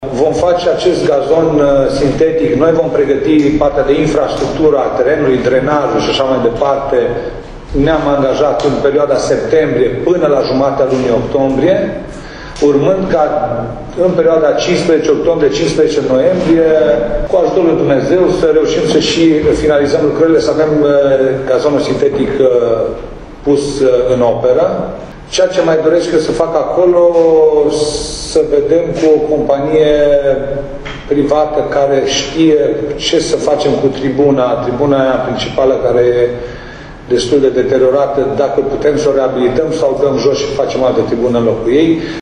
Ioan Popa spune că, din resurse locale, se vor efectua și lucrări la tribunele stadionului „Gloria”, aflate de ani buni în paragină:
Ioan-Popa-despre-teren-sintetic-si-tribune.mp3